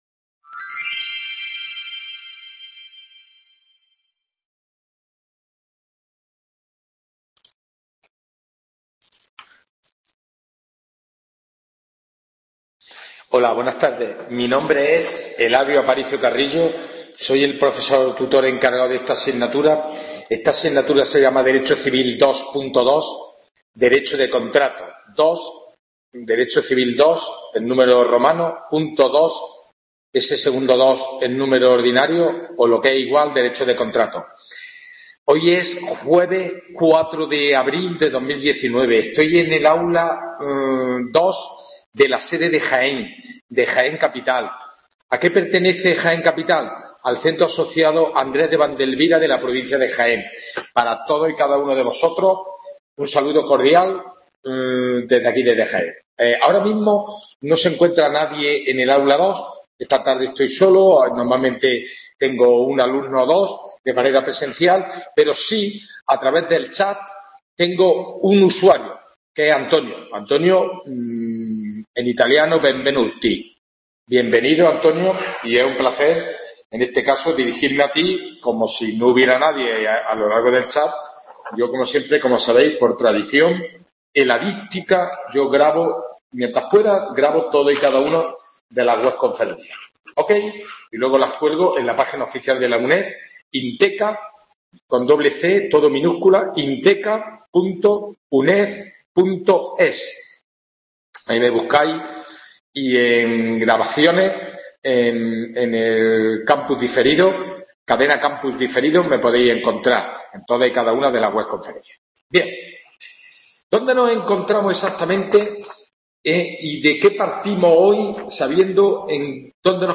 WEBCONFERENCIA